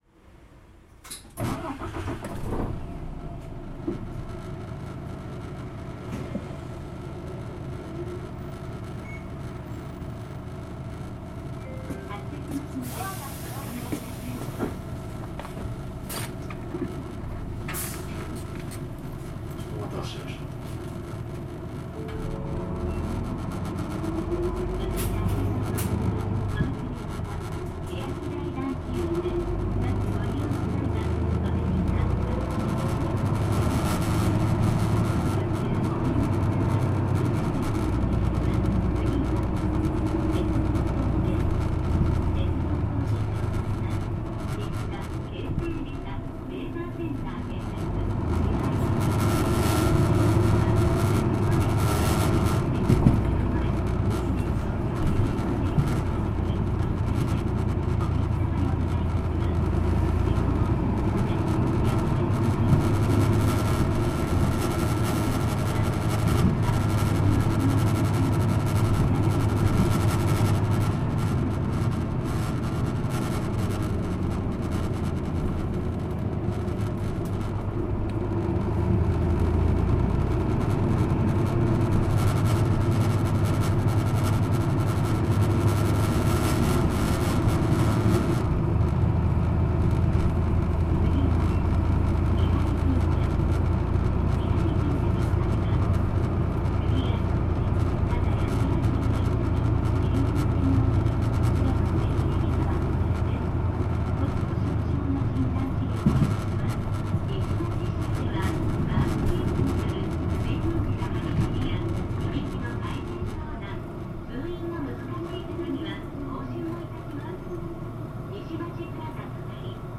全国路線バス走行音立川バス
備考：トルコンAT
立川バスでは少数派のワンステップ車．上水営業所所属で主に国21で活躍しています．LKG代ですがこの頃からアリソンATが選択されています．座席のビビり音が入ってしまいました…．